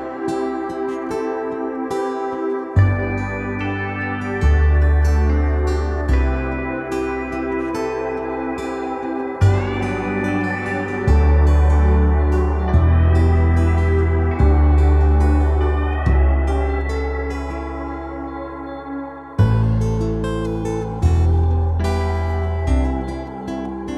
For Solo Singer Pop (2010s) 3:47 Buy £1.50